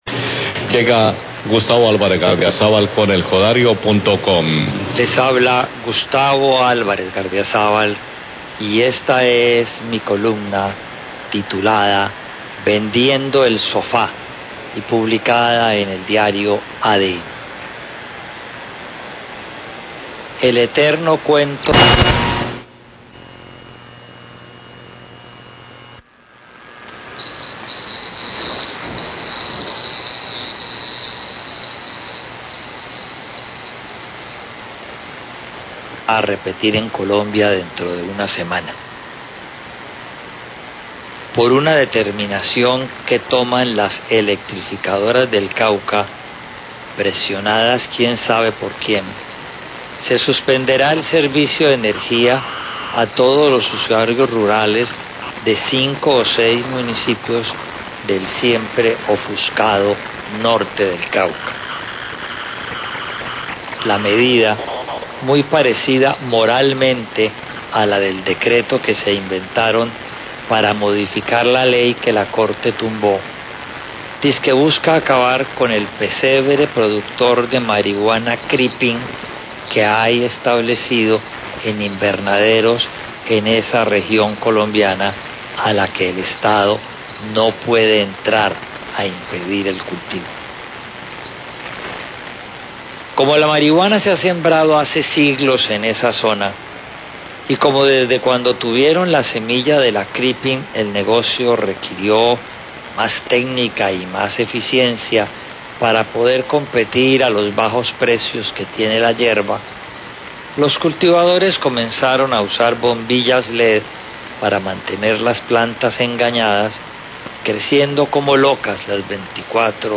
EDITORIAL SOBRE CORTE ENERGÍA EN CAUCA POR CULTIVOS ILÍCITOS
Radio